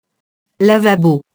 lavabo [lavabo]